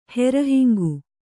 ♪ hera hingu